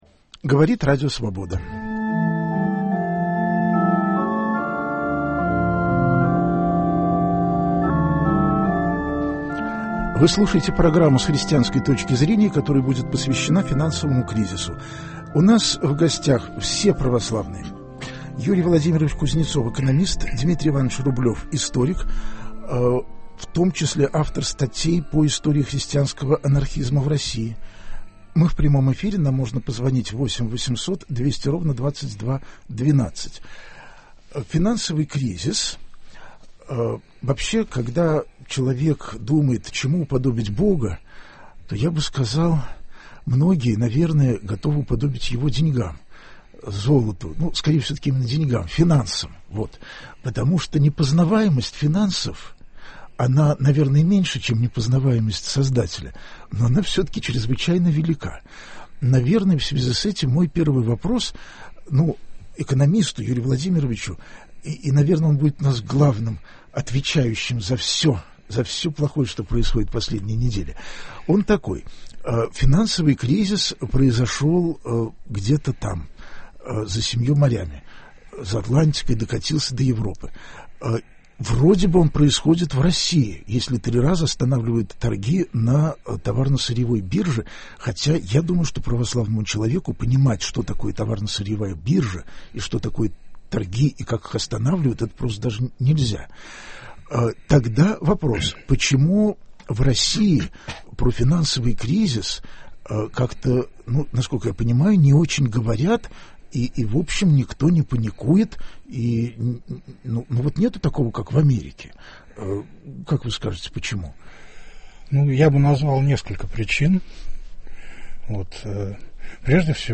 программа идет в прямом эфире - задавайте прямые вопросы, получите прямые ответы.